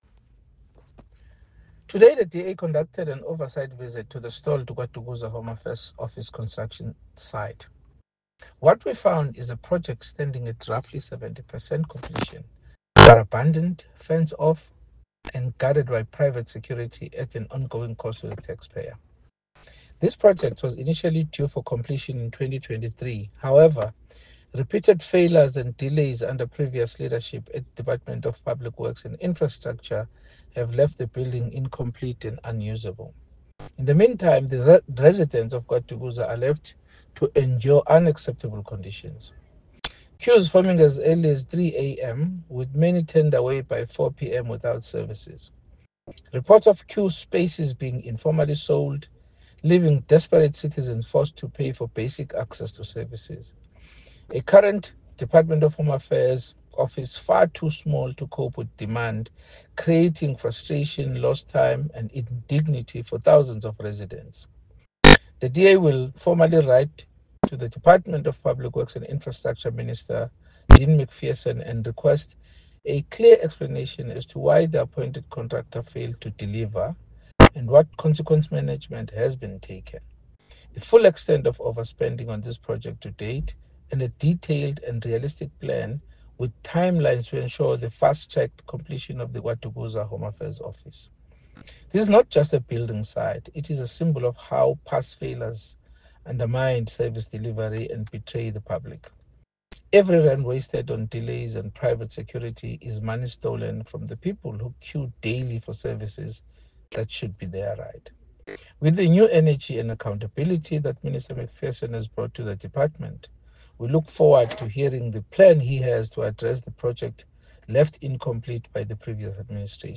soundbite by Bonginkosi Madikizela MP.